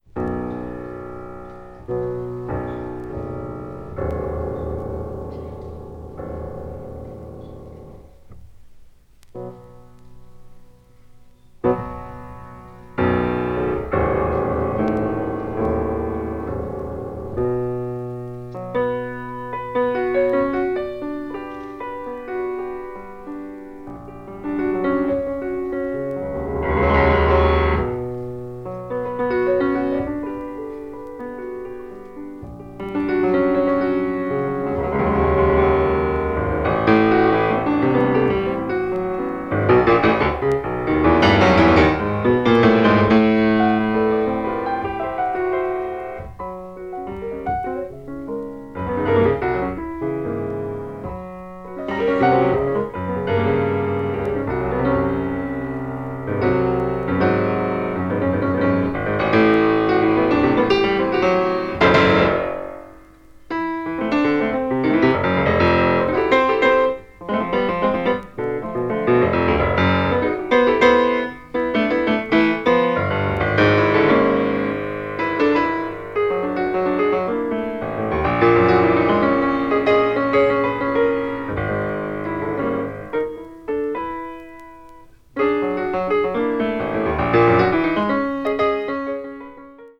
avant-jazz   free improvisation   free jazz